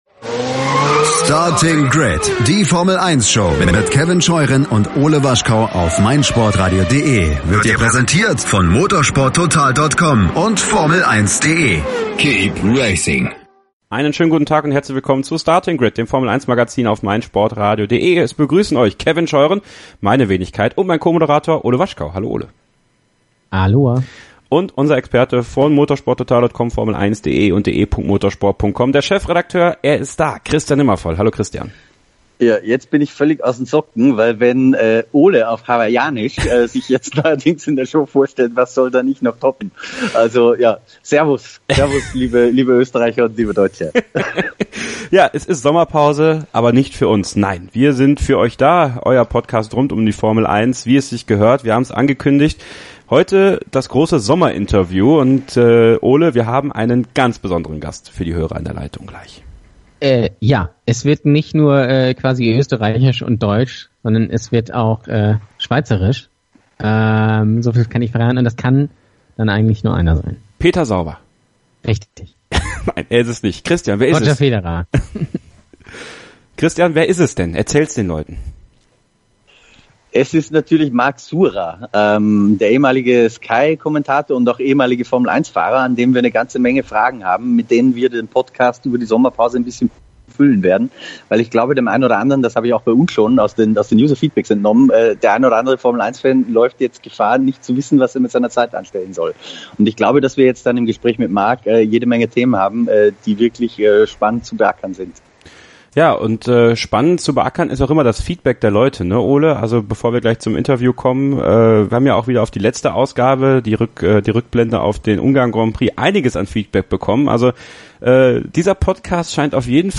Sommerinterview mit Surer ~ Starting Grid Podcast
Marc Surer ist der Gast unseres Sommerinterviews 2018.